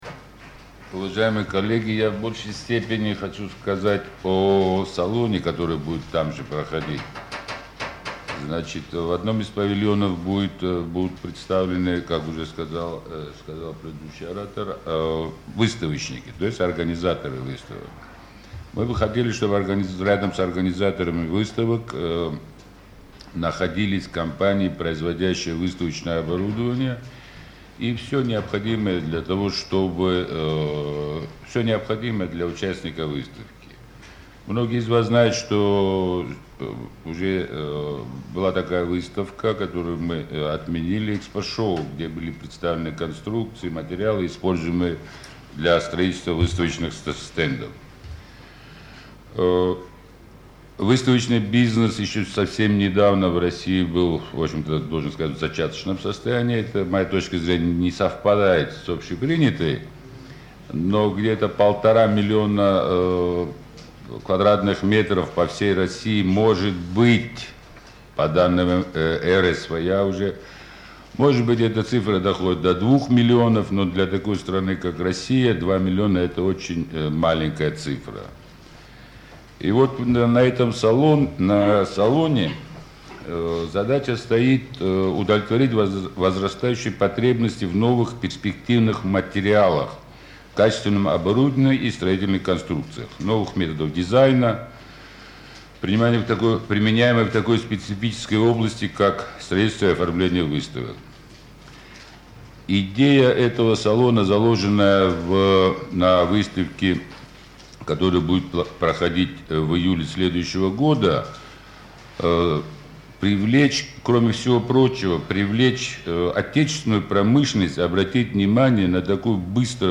12 сентября 2006 г. в 11.00 в Конгресс-центре ЦВК ЭКСПОЦЕНТР состоится пресс-конференция, посвященная запуску нового смотра "Международного Форума выставочной индустрии" 5pEXPO 2007.
Выступление